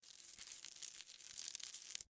insects-crawling-01.ogg